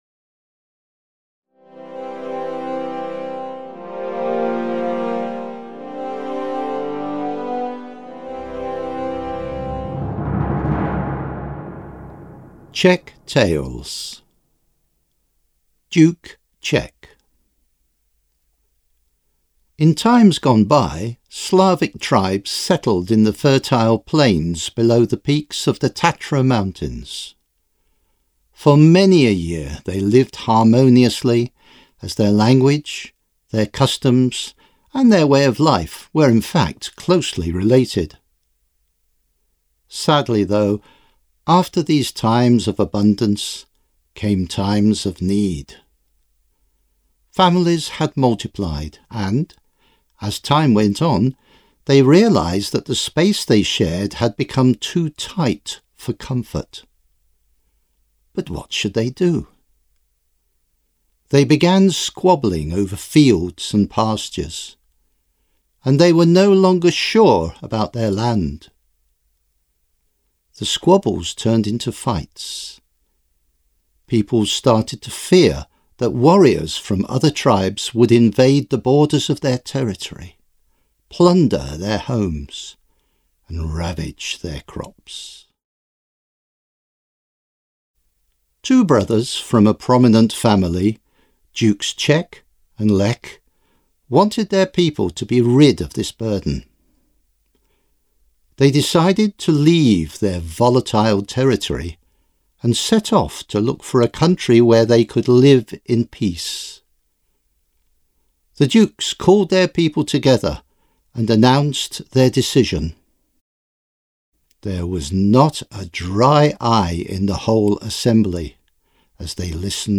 Najdete mezi nimi pověsti: O Čechovi, O Krokovi a jeho dcerách, O Bivoji, Libuše a Přemysl, Dívčí válka a další. Texty jsou určeny pro středně pokročilé studenty angličtiny, vypráví rodilý mluvčí.